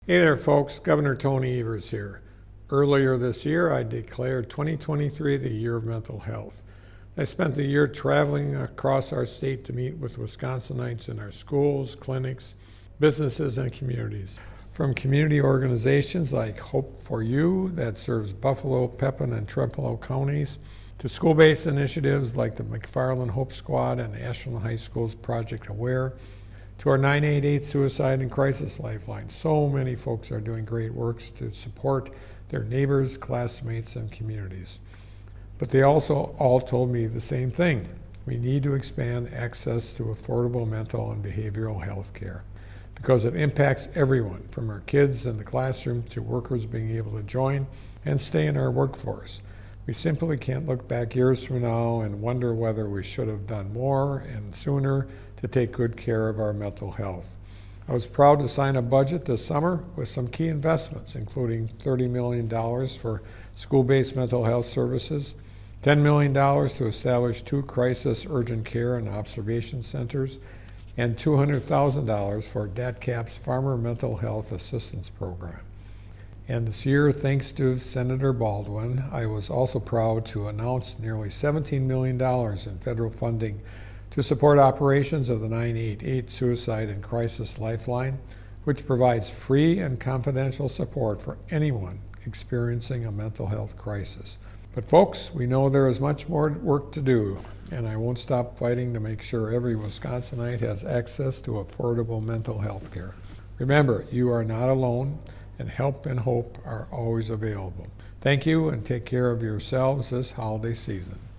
MADISON — Gov. Tony Evers today delivered the Democratic Radio Address on the Year of Mental Health and his efforts over the past year to invest in expanding access to mental and behavioral healthcare.